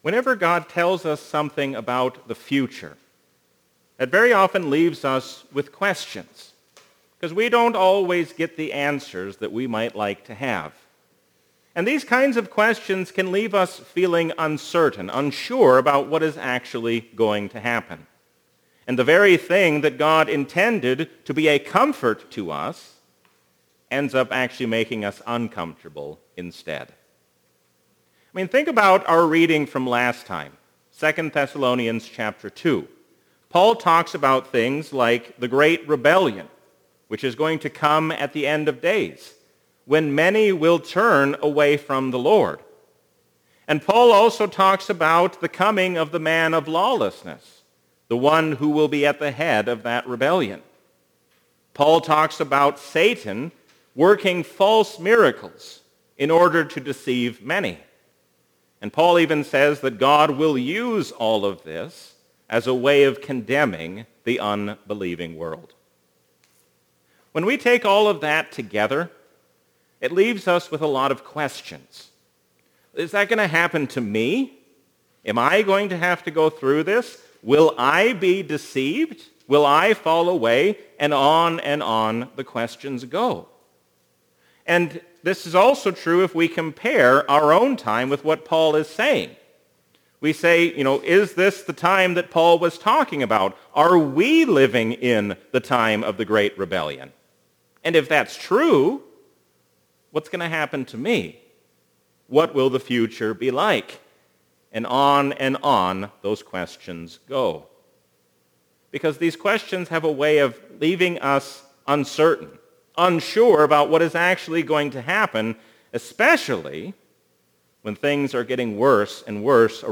A sermon from the season "Trinity 2021." We can be confident even when facing a difficult future, because God chose us for Himself.